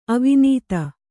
♪ avinīta